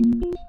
logout.wav